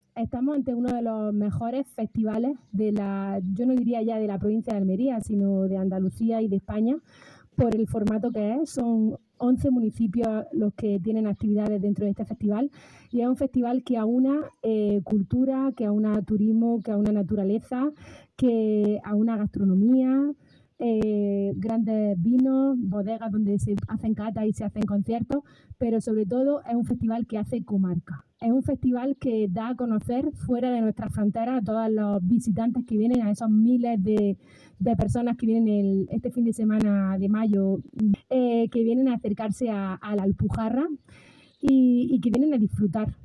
El Jardín-Huerto Villa María de Canjáyar ha sido escenario este mediodía de sábado de la presentación oficial de la quinta edición de Festival ‘Murmura Alpujarra’ que, convertido en todo un referente de su formato, se desarrollará los días 16, 17 y 18 de mayo en distintas sedes: Alboloduy, Alcolea, Almócita, Beires, Canjáyar, Fondón, Fuente Victoria, Laujar de Andarax, Padules, Paterna del Río y Rágol.